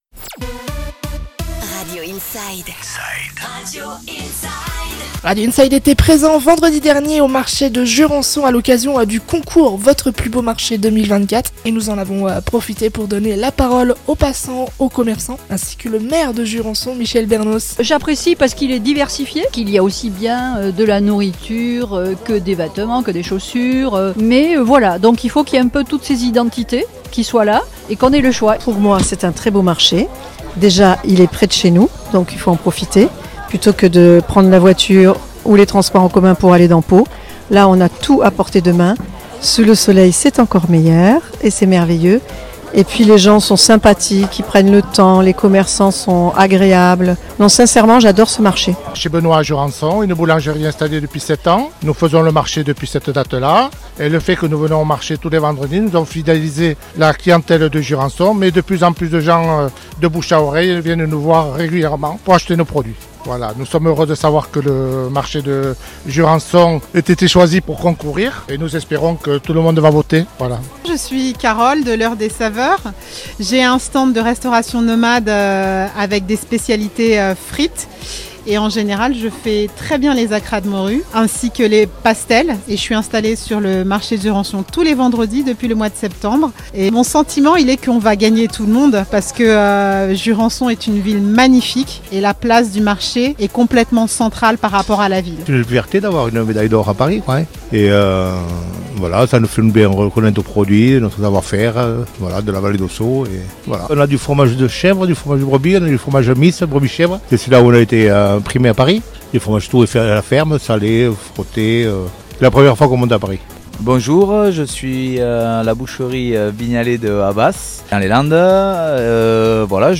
Interview au marché de Jurançon pour le concours "Votre plus beau marché 2024" sur Radio Inside